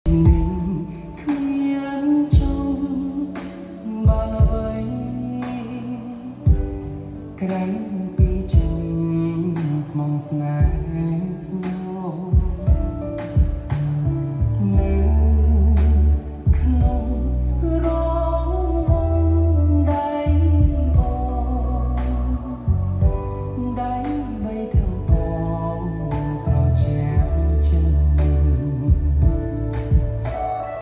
រៀប LIVE BAND ជូនភ្ញៀវ JBL Sound Effects Free Download